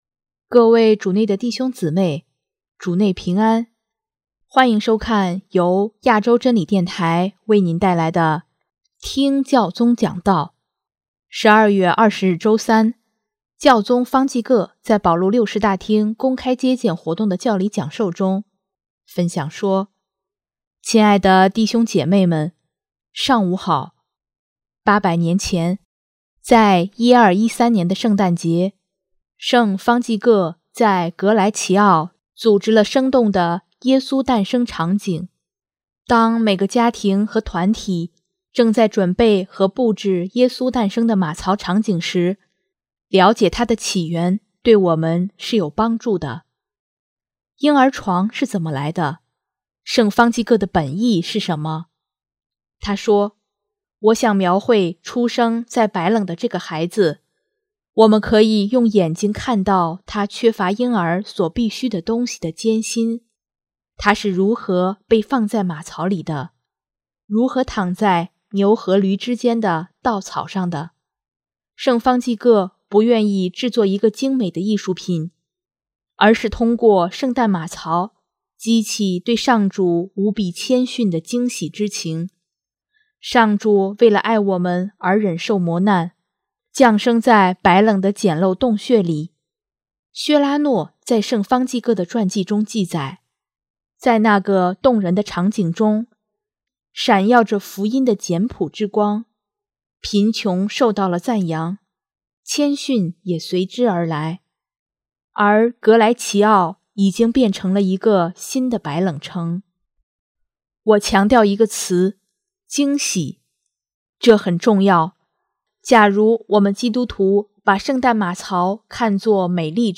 12月20日周三，教宗方济各在保禄六世大厅公开接见活动的教理讲授中，分享说：